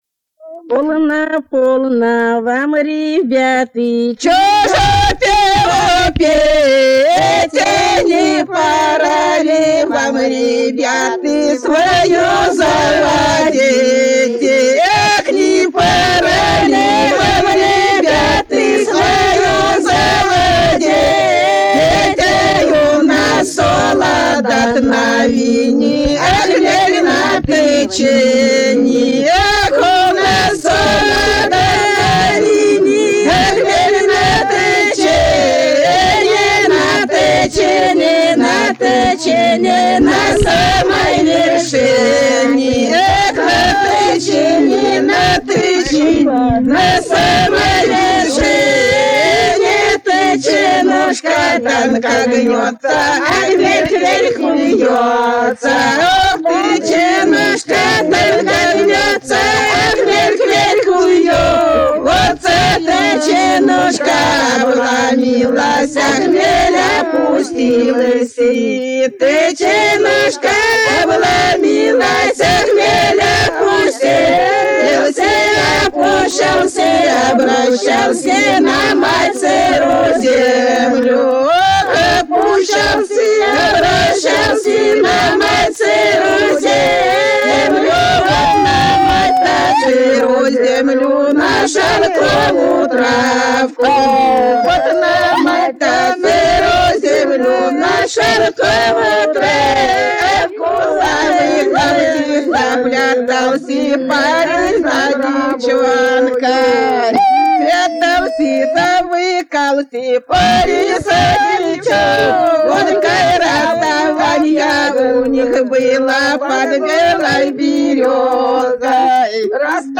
Народные песни Касимовского района Рязанской области «Полно, полно вам, ребяты», плясовая.